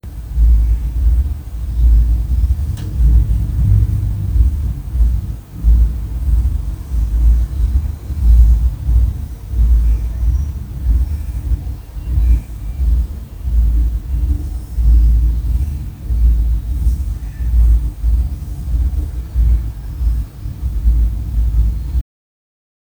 the sound is around 50Hz, it sounds a lot like what I often hear from cars with the big subwoofers. You hear the boom... boom... boom sound hundreds of meters away.